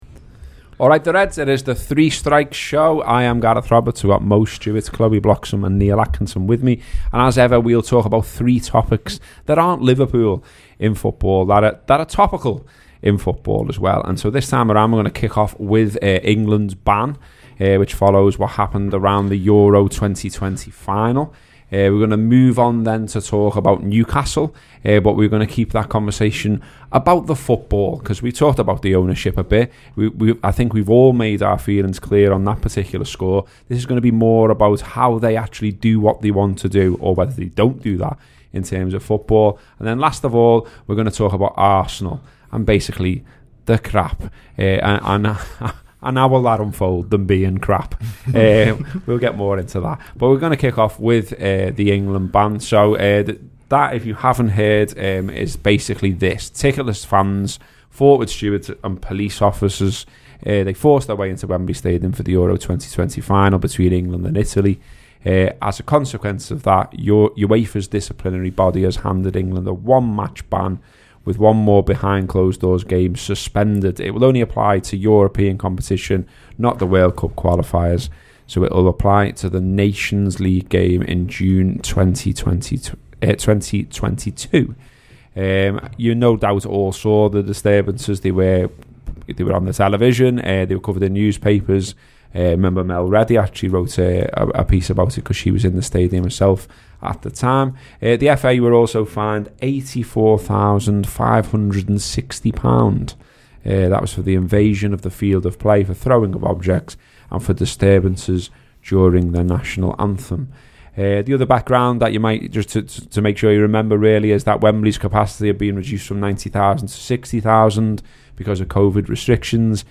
The three talking points the panel discuss are the ban on England fans, Newcastle’s next manager and the latest on Arsenal.